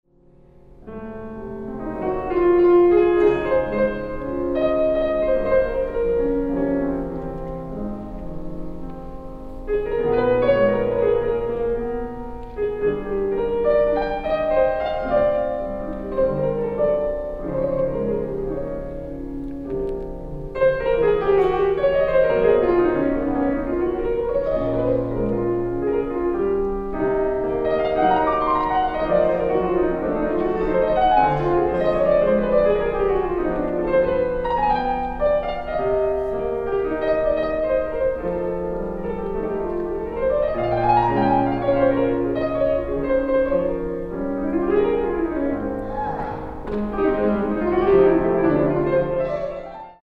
ライブ・アット・カーネギーホール、ニューヨーク 01/29/2009
※試聴用に実際より音質を落としています。